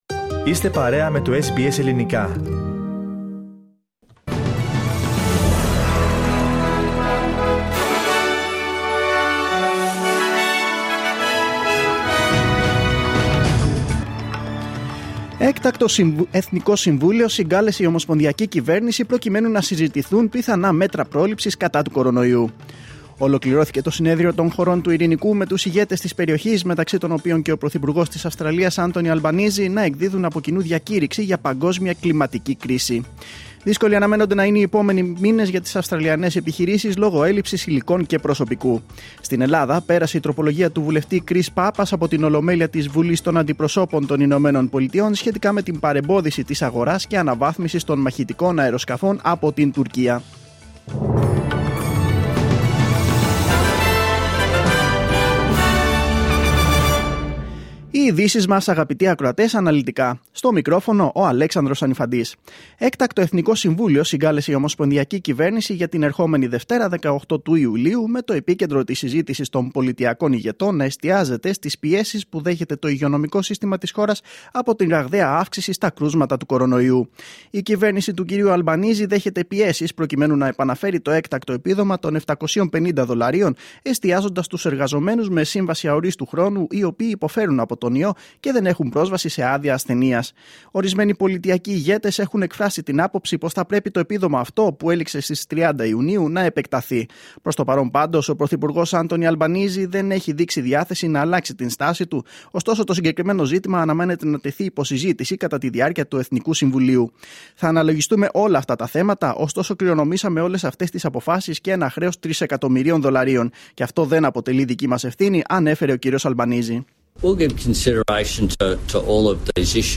Δελτίο Ειδήσεων Παρασκευή 15.7.2022
News in Greek. Source: SBS Radio